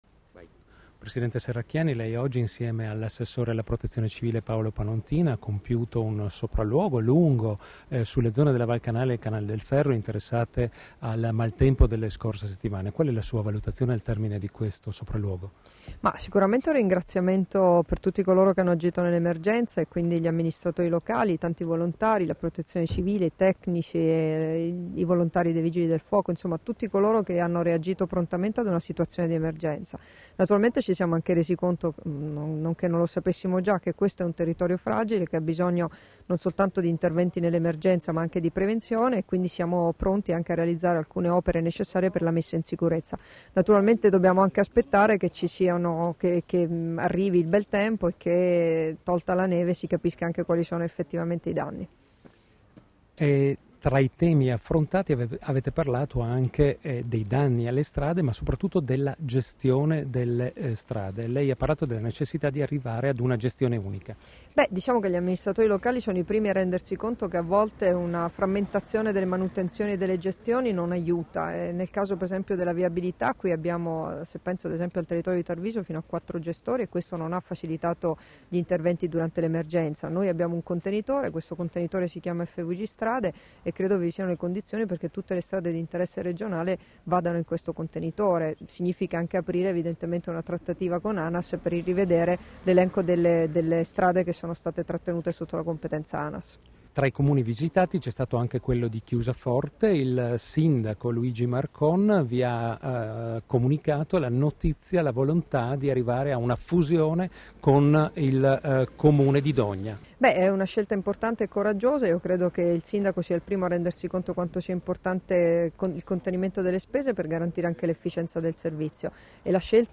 Dichiarazioni di Debora Serracchiani (Formato MP3) [2094KB]
rilasciate a margine del sopralluogo in Val Canale - Canal del Ferro a Moggio Udinese, Chiusaforte, Tarvisio e Pontebba, il 18 febbraio 2014